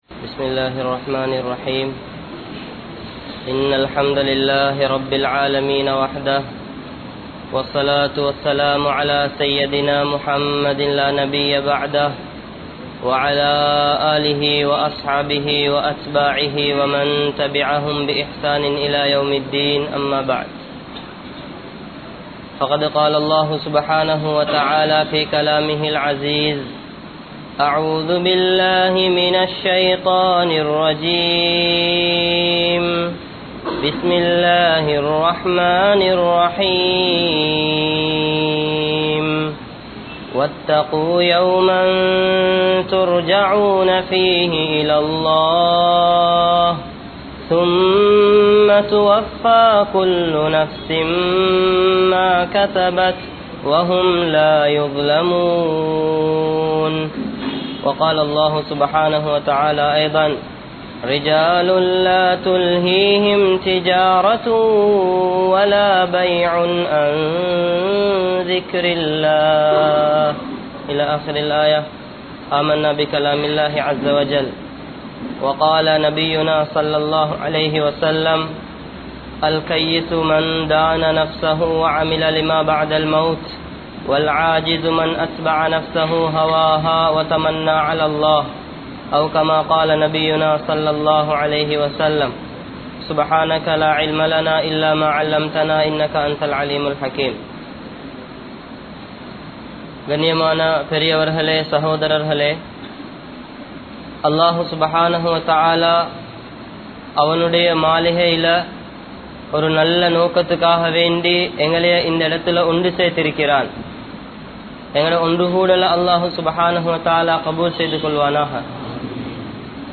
Nallavarhalai Allah Therivu Seivan (நல்லவர்களை அல்லாஹ் தெரிவு செய்வான்) | Audio Bayans | All Ceylon Muslim Youth Community | Addalaichenai